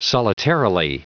Prononciation du mot solitarily en anglais (fichier audio)
Prononciation du mot : solitarily